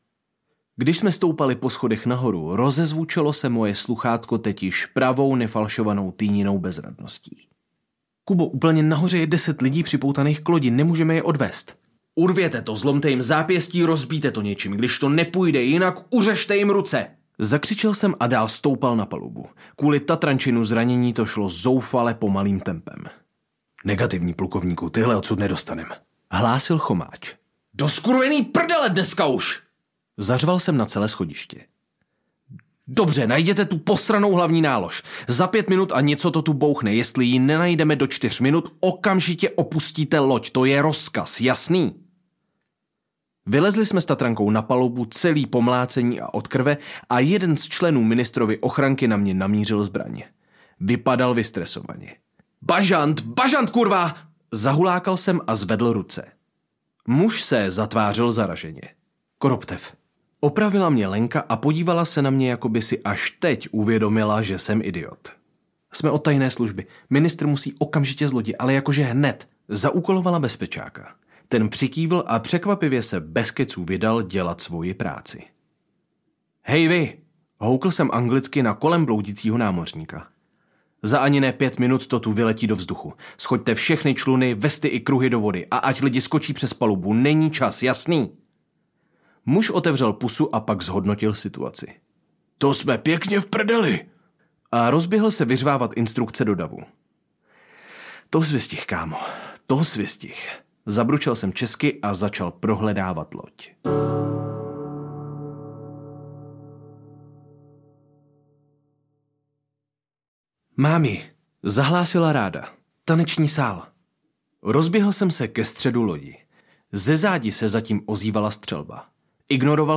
Tanec papírových draků audiokniha
Ukázka z knihy